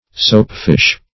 Soapfish \Soap"fish`\, n. (Zool.)